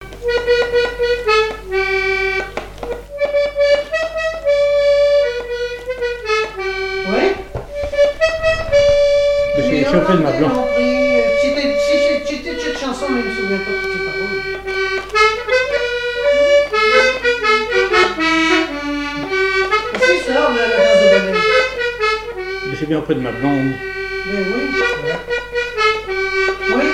accordéon diatonique
Pièce musicale inédite